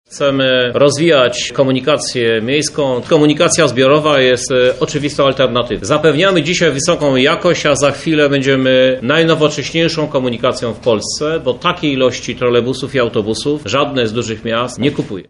O planach związanych z ulepszaniem komunikacji miejskiej mówi Prezydent Lublina, Krzysztof Żuk.